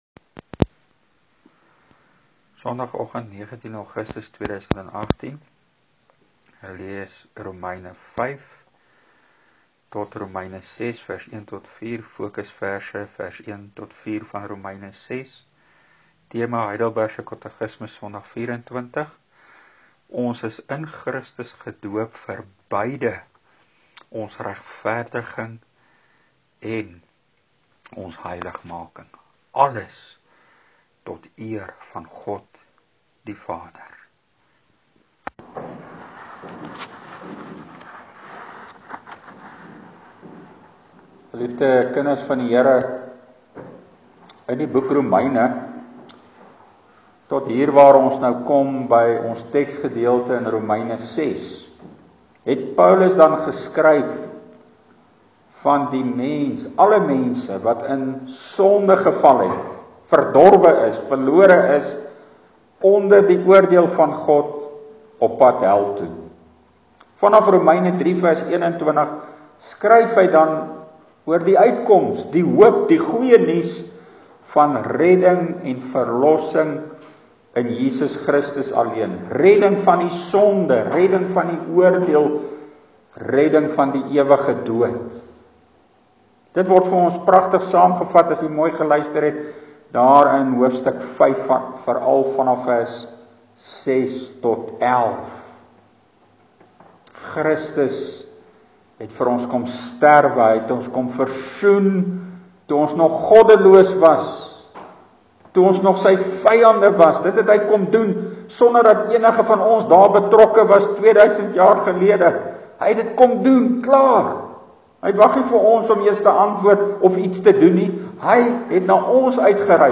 Preek: In Christus geplant om vrugte van dankbaarheid voort te bring (Rom. 6:1-4)